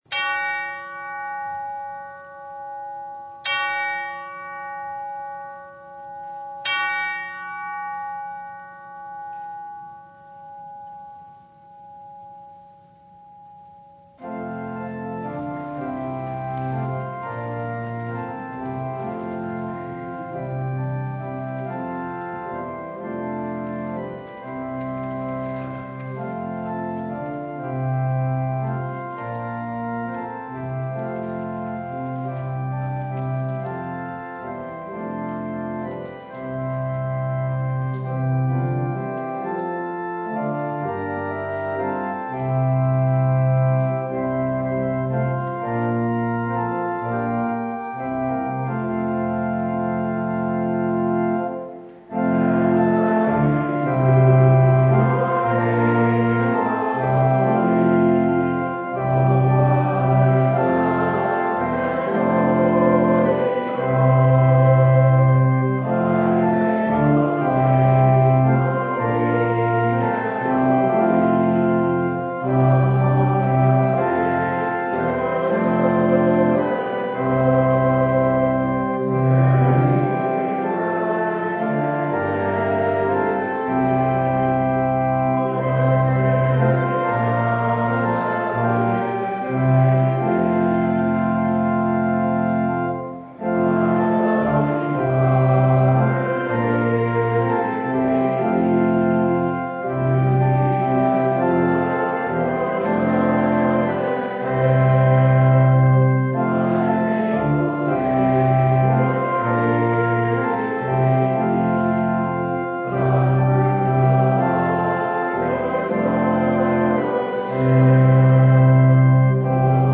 Mark 1:4-11 Service Type: Sunday Jesus is in the water!